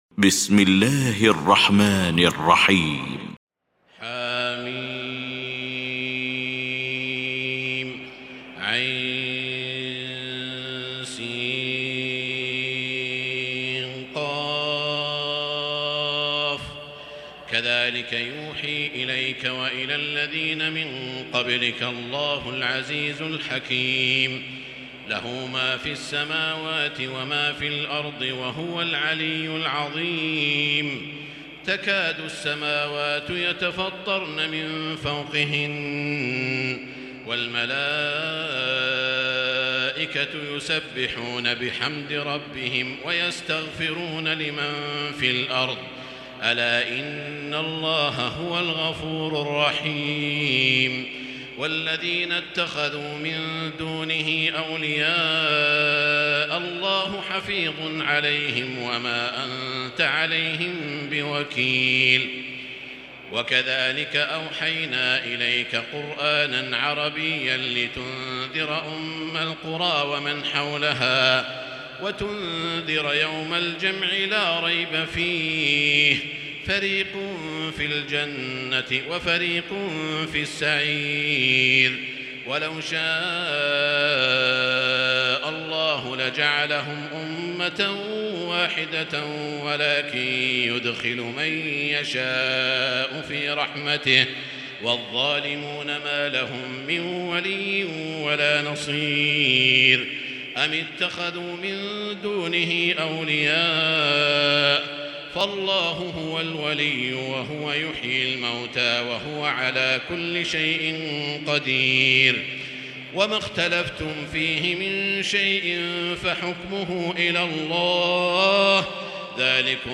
المكان: المسجد الحرام الشيخ: سعود الشريم سعود الشريم الشورى